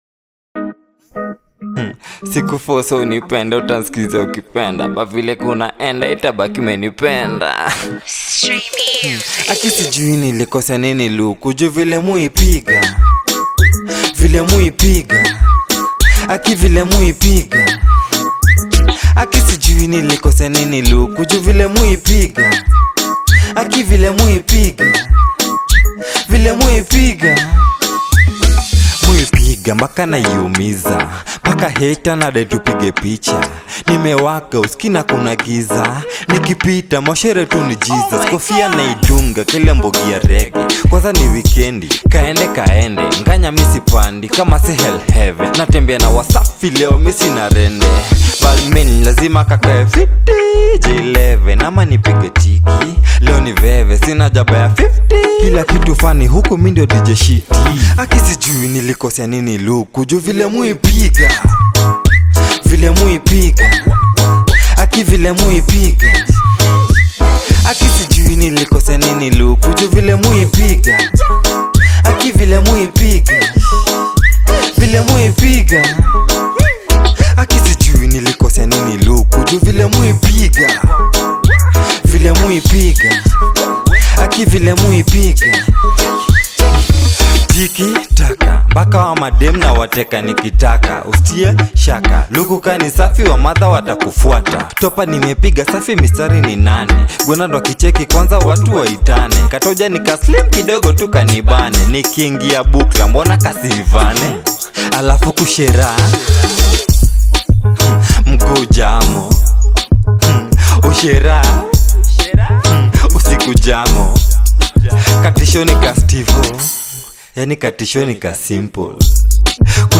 Kenyan rapper
Kenyan rap songs